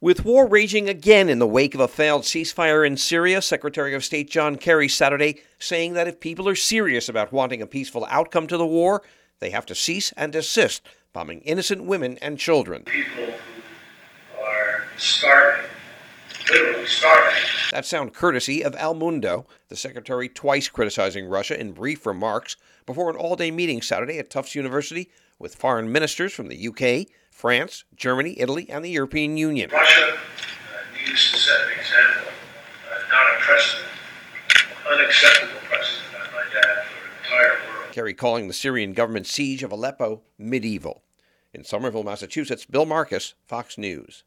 (SOMERVILLE, MA) SEPT 24 – SECRETARY OF STATE JOHN KERRY CALLING OUT RUSSIA SATURDAY MORNING AT A MEETING OF EUROPEAN MINISTERS HELD OUTSIDE OF BOSTON.